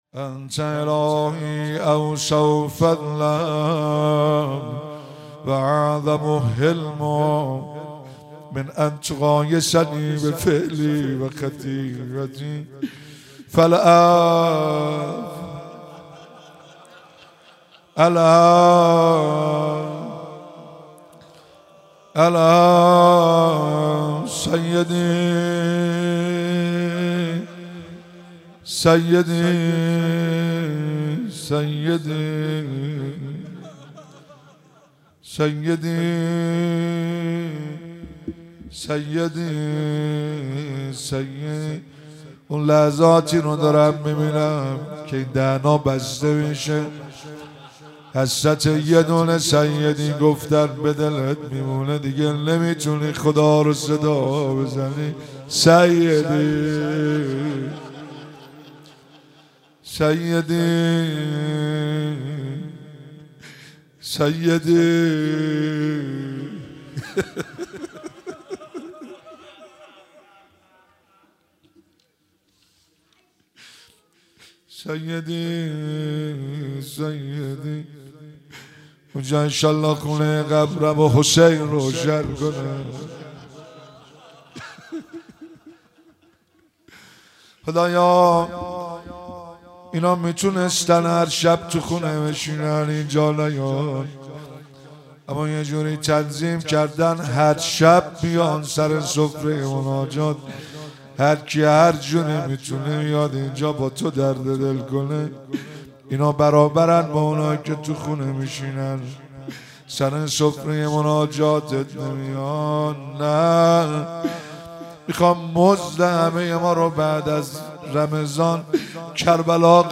مراسم مناجات خوانی شب هفتم ماه رمضان 1444